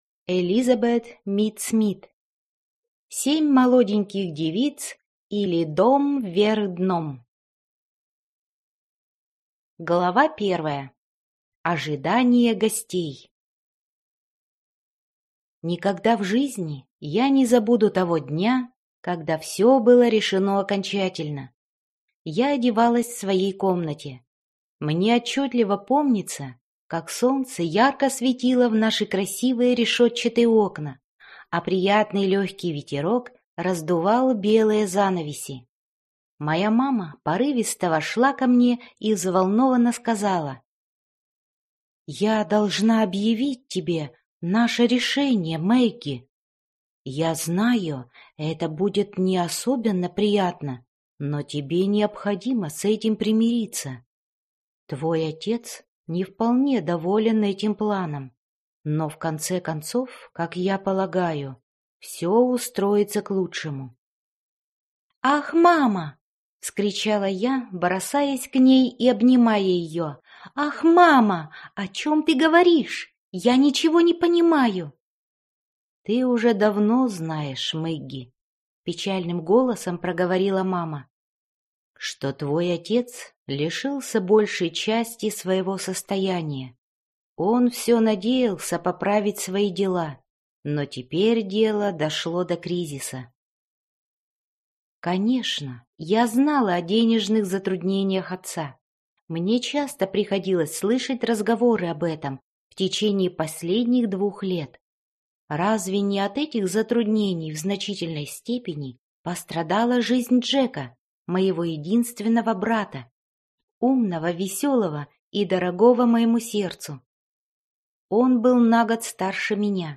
Аудиокнига Семь молоденьких девиц, или Дом вверх дном | Библиотека аудиокниг